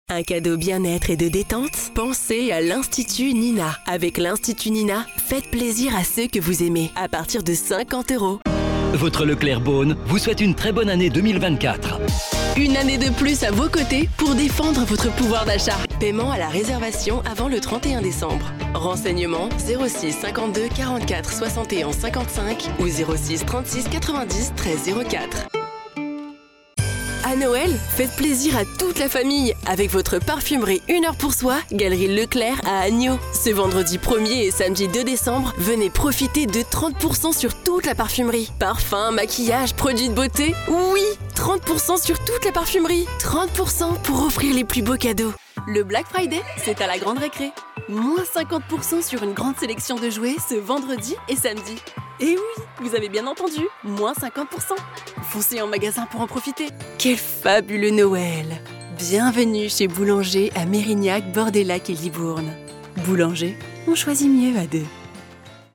Radio commercial demo
I have a medium deep voice with a large voice palette and many crazy characters at the service of your fairy tails, video games and commercials.